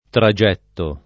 vai all'elenco alfabetico delle voci ingrandisci il carattere 100% rimpicciolisci il carattere stampa invia tramite posta elettronica codividi su Facebook tragittare v.; tragitto [ tra J& tto ] — ant. tragettare : tragetto [ tra J$ tto ]